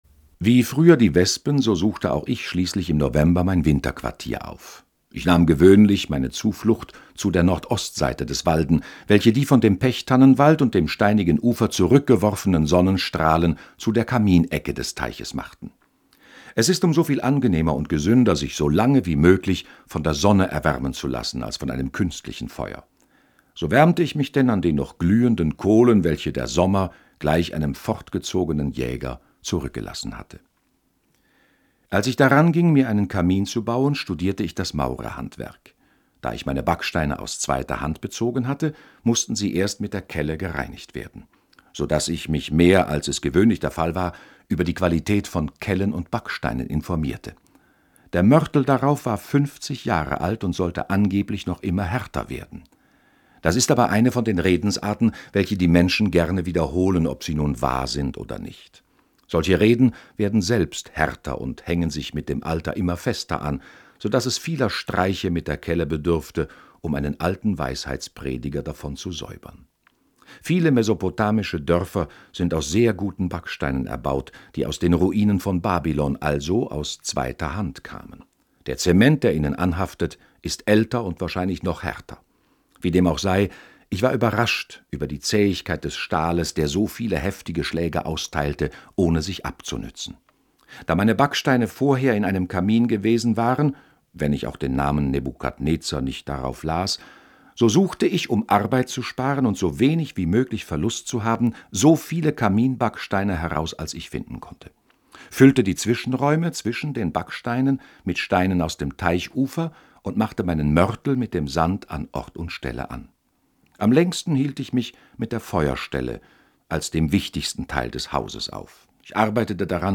Lesungen Podcast